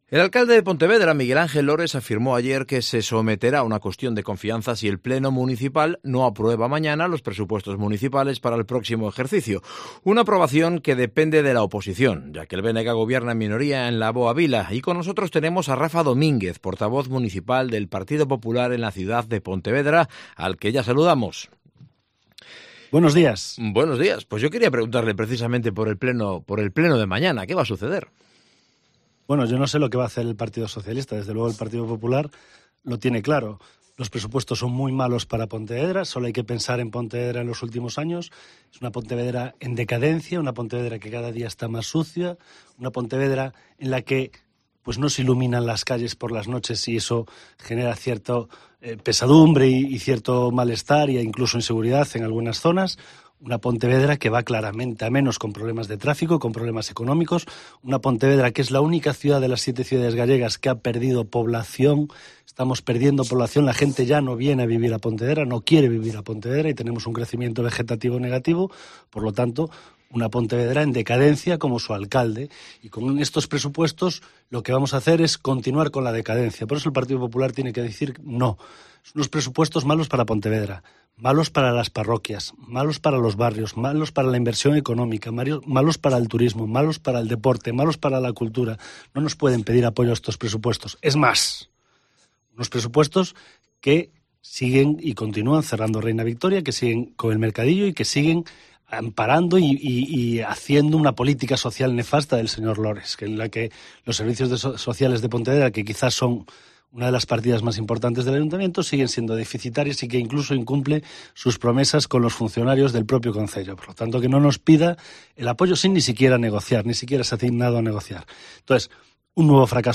Entrevista con Rafa Domínguez, portavoz del PP en Pontevedra
AUDIO: Entrevista patrocinada por el Grupo Municipal del Partido Popular de Pontevedra